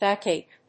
音節báck・àche 発音記号・読み方
/ˈbækeɪk(米国英語), ˈbæˌkeɪk(英国英語)/